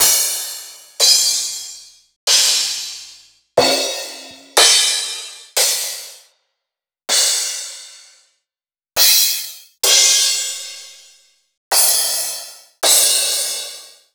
012_Crash.wav